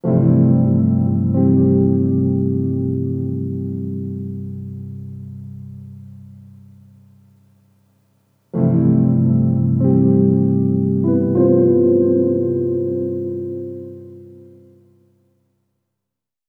Treated Piano 07.wav